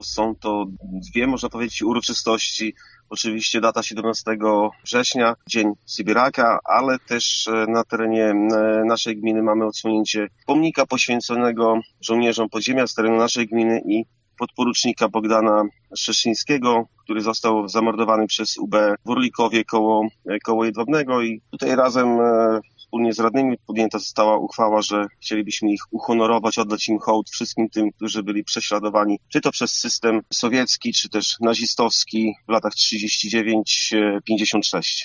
O wspomnianym wydarzeniu mówi Burmistrz Jedwabnego, Adam Mariusz Niebrzydowski: